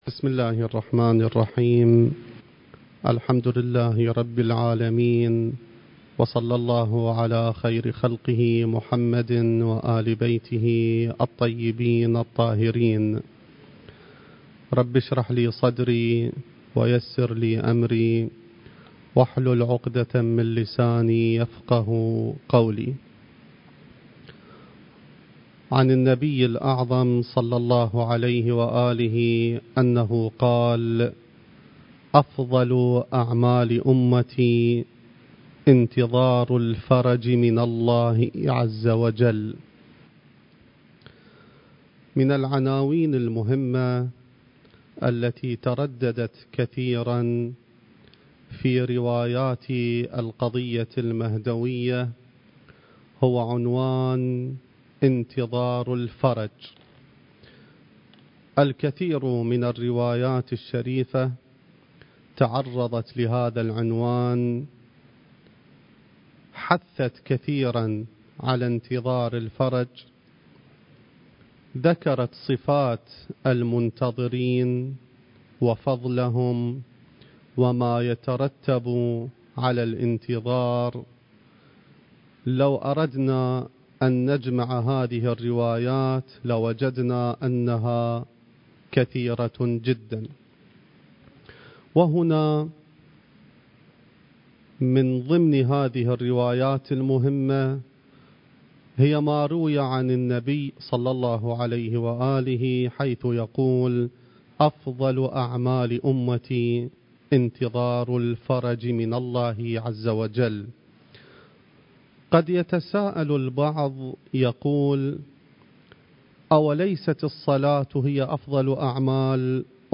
المكان: العتبة العلوية المقدسة الزمان: ذكرى ولادة الإمام المهدي (عجّل الله فرجه) التاريخ: 2020